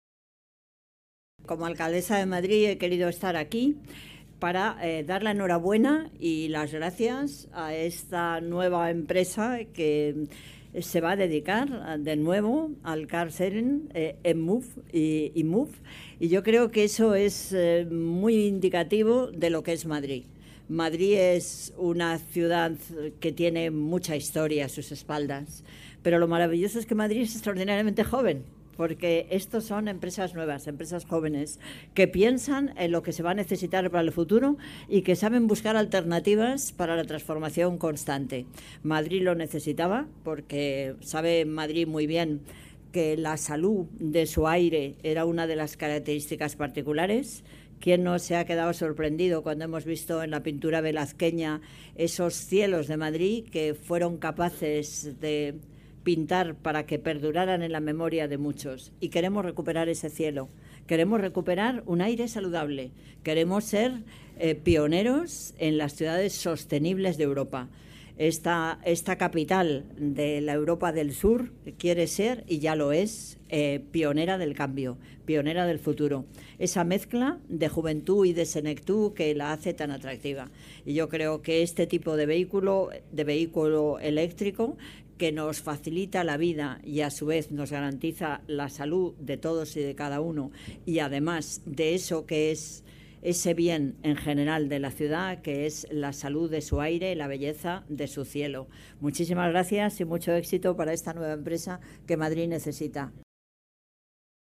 La alcaldesa asiste a la presentación de emov, un nuevo servicio para compartir coche que pone en circulación 500 vehículos eléctricos
Nueva ventana:Manuela Carmena habla de esta nueva iniciativa de vehículo eléctrico en la ciudad